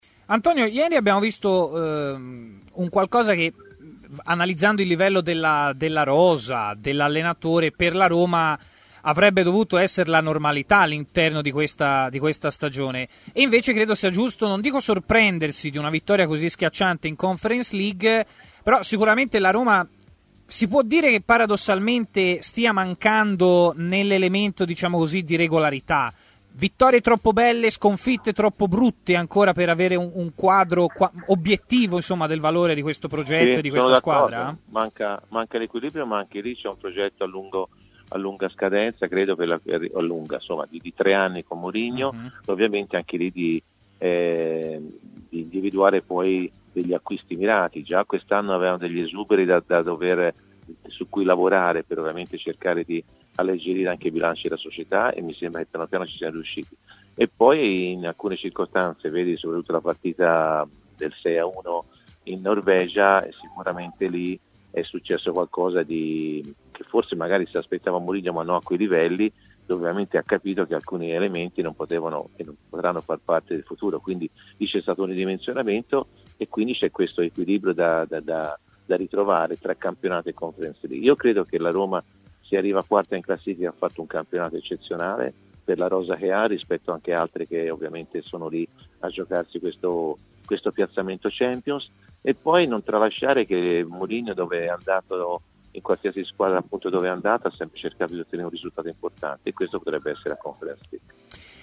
Antonio Di Gennaro è intervenuto a Stadio Aperto, trasmissione pomeridiana di TMW Radio.